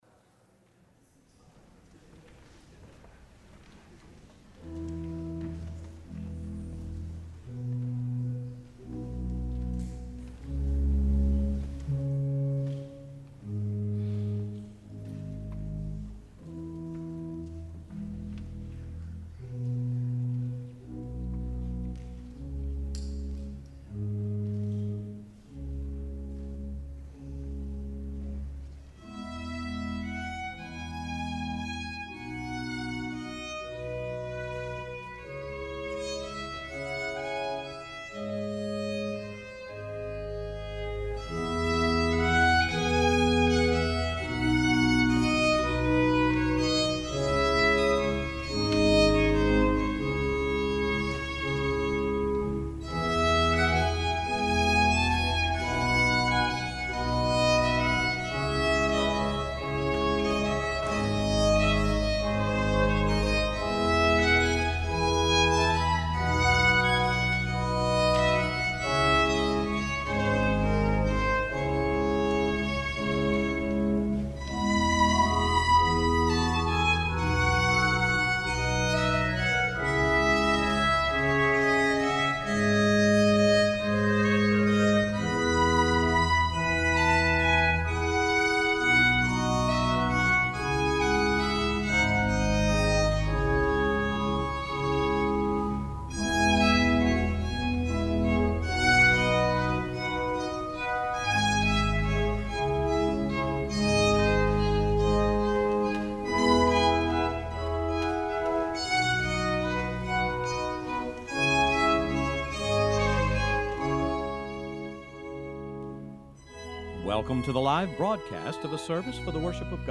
violin
organ